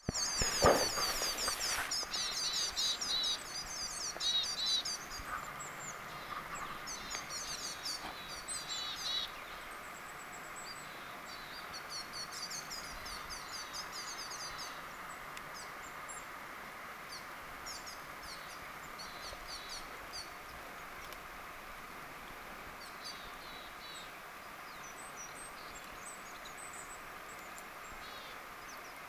onko tuo kuusitiaisten ääntelyä?
onko_nuo_kuusitiaisia_vai_mita.mp3